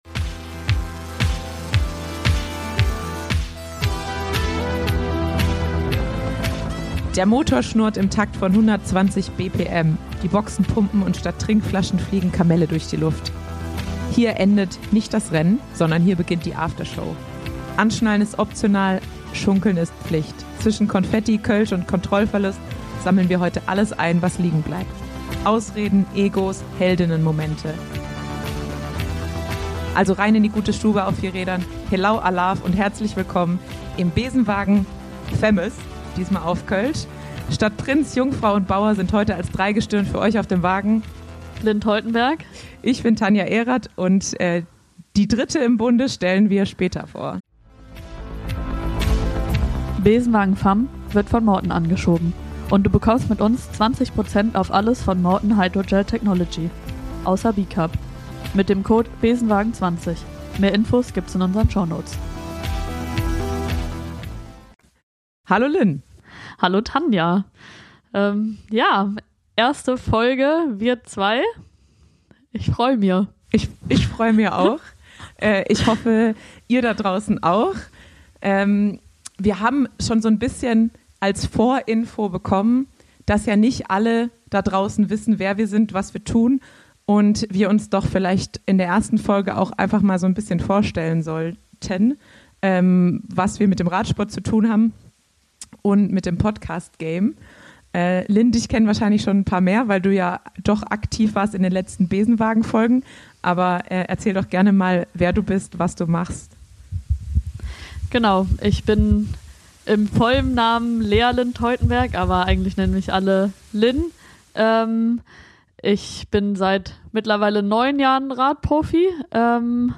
Das neue Host-Duo sammelt euch und Profis auf, um über das Leben auf und neben dem Rad zu plaudern.